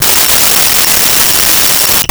Cell Phone Ring 02
Cell Phone Ring 02.wav